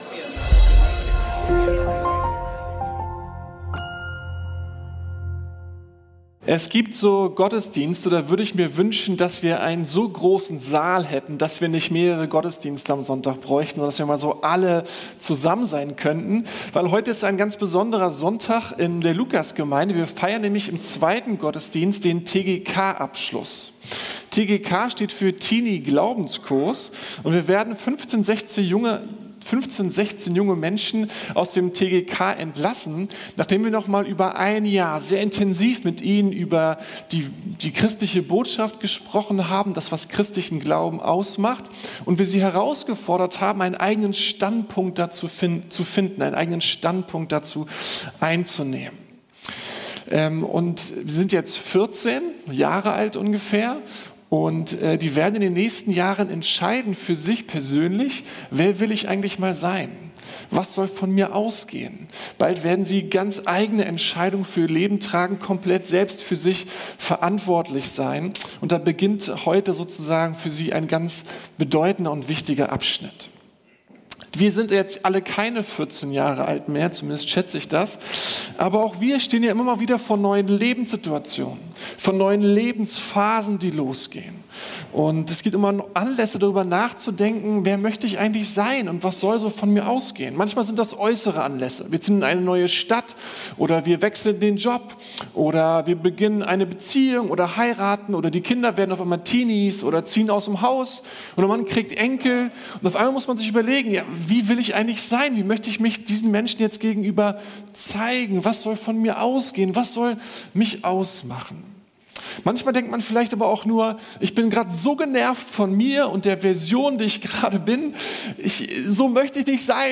Kraft zum Widerstehen ~ Predigten der LUKAS GEMEINDE Podcast